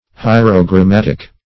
Search Result for " hierogrammatic" : The Collaborative International Dictionary of English v.0.48: Hierogrammatic \Hi`er*o*gram"mat"ic\, a. [Cf. F. hi['e]rogrammatique.]